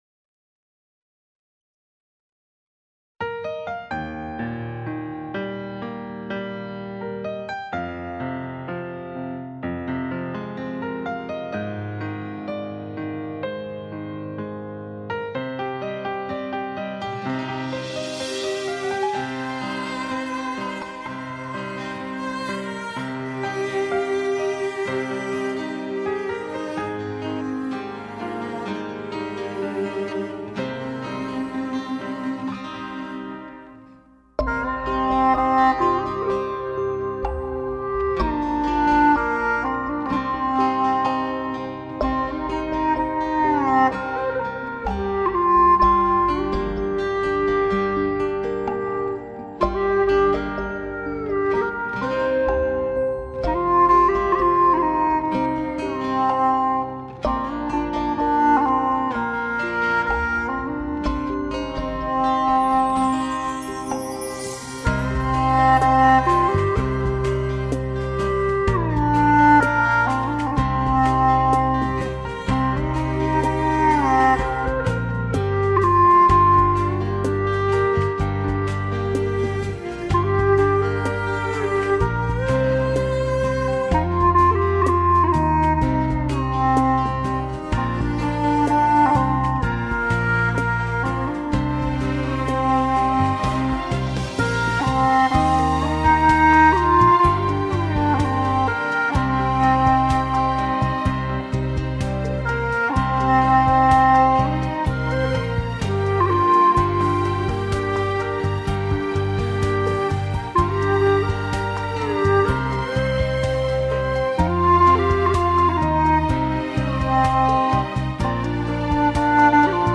调式 : 降E 曲类 : 古风